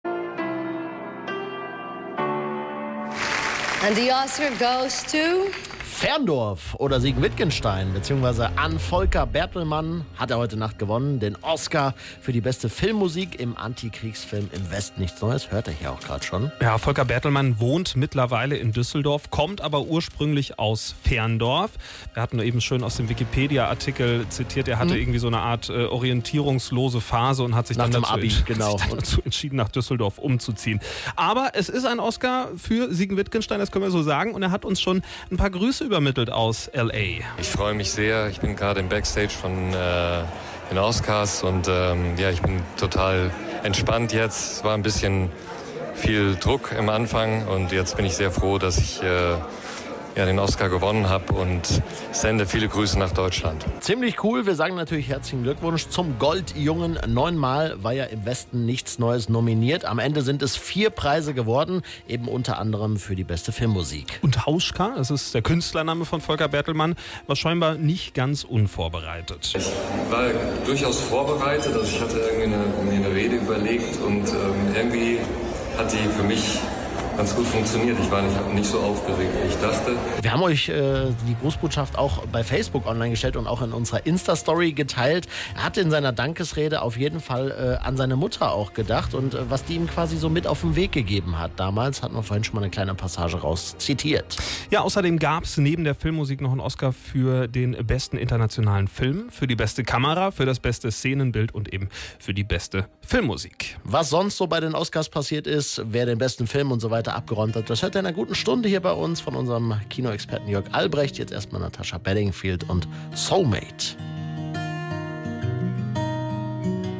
Radio Siegen ließ er in der Nacht des Triumphes ein Grußwort zukommen.